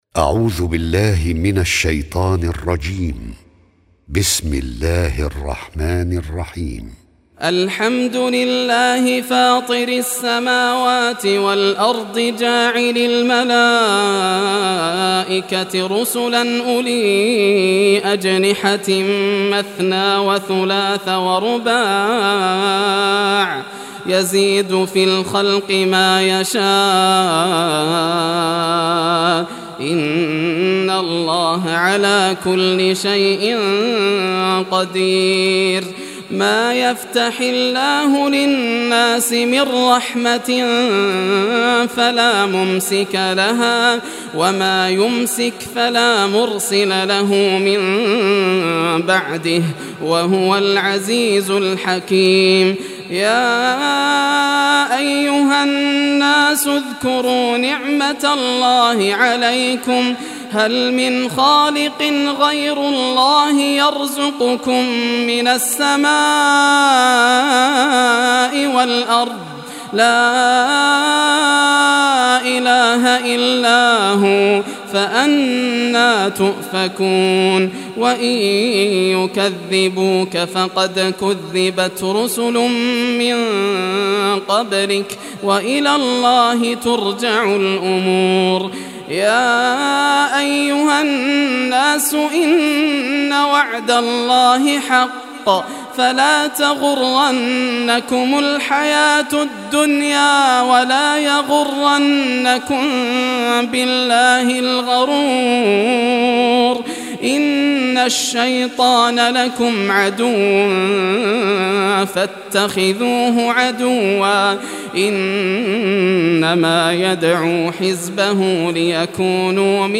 Surah Fatir Recitation by Yasser al Dosari
Surah Fatir, listen or play online mp3 tilawat / recitation in Arabic in the beautiful voice of Sheikh Yasser al Dosari. Download audio tilawat of Surah Fatir free mp3 in best audio quality.